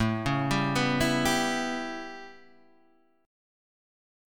A9 chord {5 4 5 4 5 3} chord